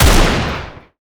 Anarchy/sound/weapons/arccw_ue/m60/fire-02.ogg at 85dfb7089e24a797869f8a9e74420f6cf1209b92
fire-02.ogg